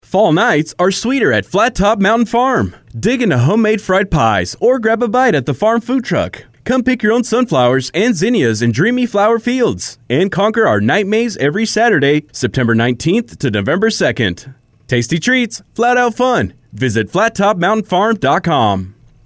Tenor – Swap A Spot